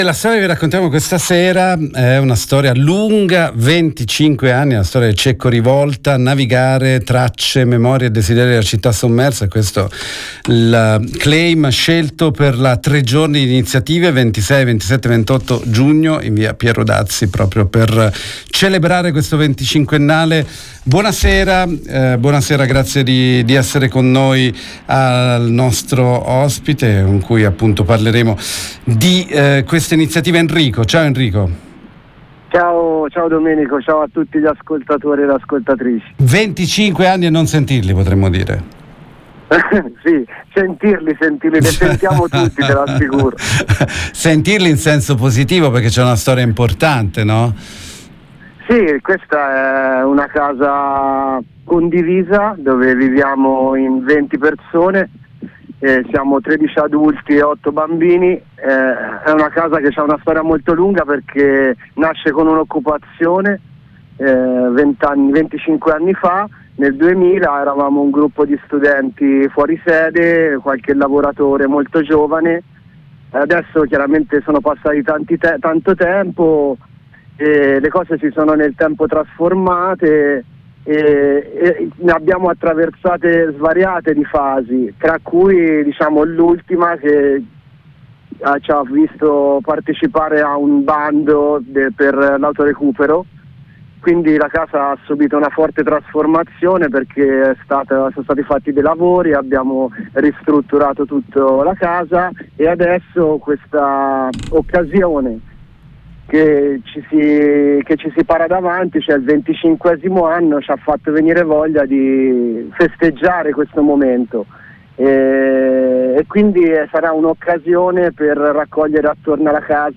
Cecco RivoltaUna tre giorni di eventi per celebrare i 25 anni del Cecco Rivolta, in  via Dazzi 3, Firenze. 26-27-28 giugno 2025. ASCOLTA L’INTERVISTA